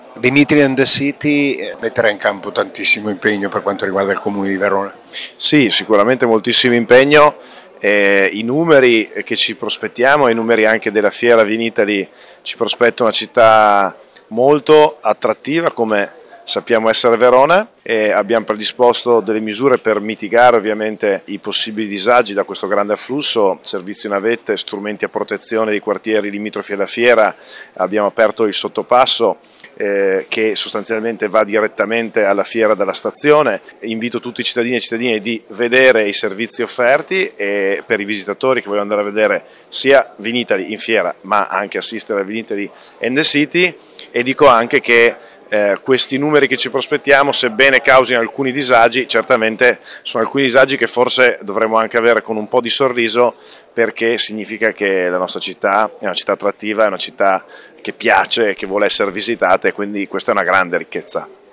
Di seguito le interviste